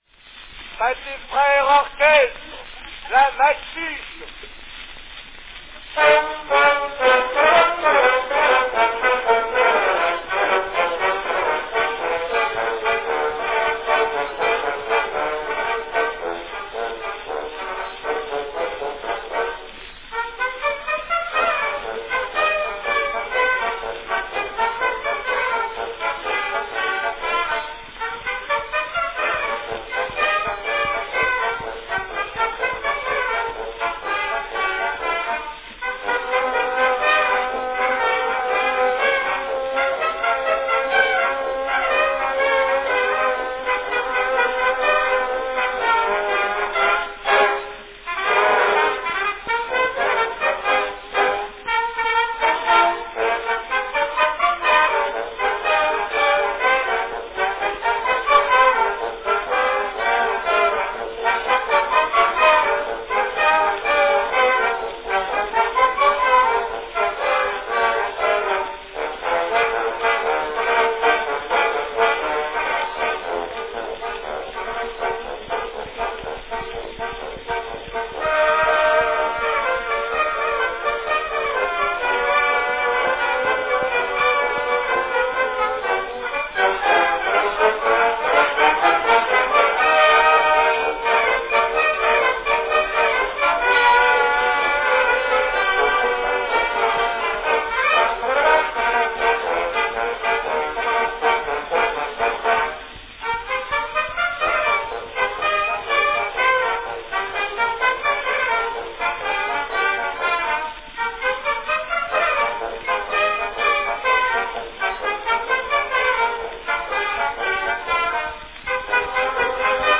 A fine recording by the French Pathé Orchestra performing La Mattchiche from 1905.
Category Orchestra (Marche populaire)
Performed by l'Orchestre Pathé Frères
Announcement "Pathé Frères Orchestre (or-KES'-{tre}), La Mattchiche."
This Spanish walk (proto-samba dance) number was written in 1905 by Charles Borel-Clerc (1879-1959).
This recording is on a Pathé 3½" 'salon' size cylinder.